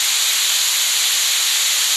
steam_1.ogg